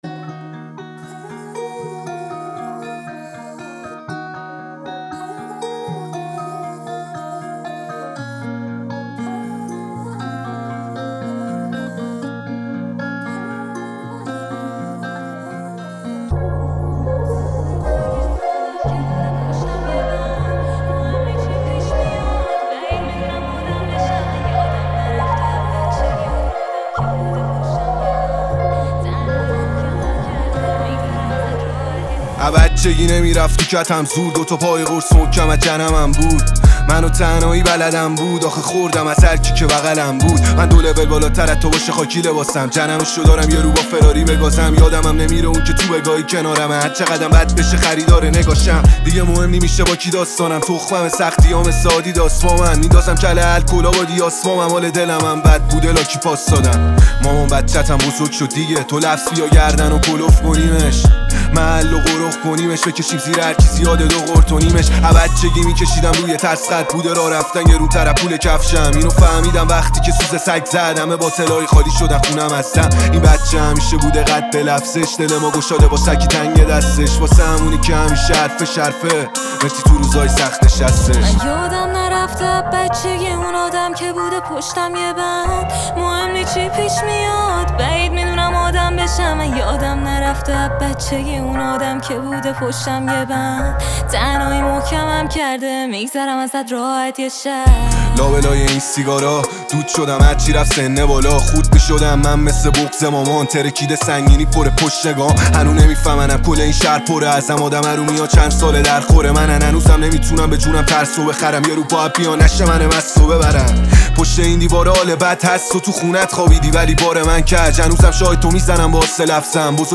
رپ